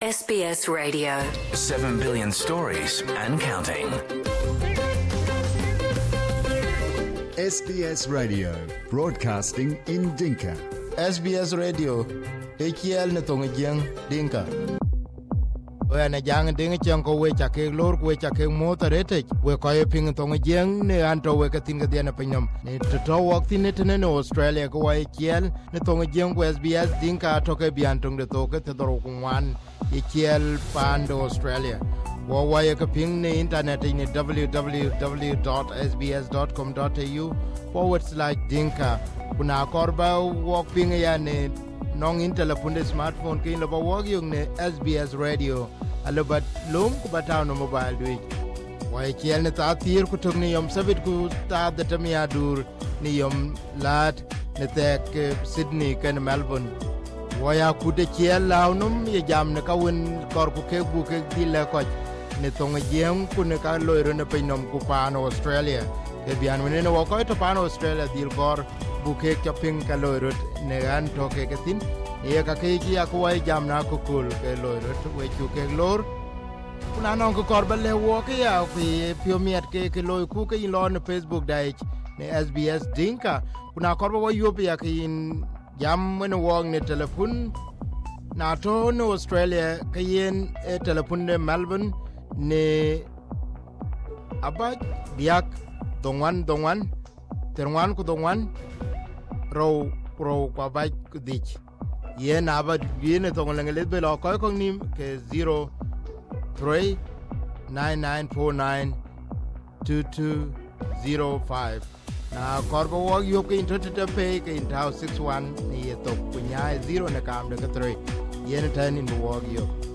In 2007, Akuien was arrested on the charges of corruption. Arthur Akuien Chol was talking to us from Sydney, Australia.
Interview with South Sudan former Minsiter of Finance Arthur Akuein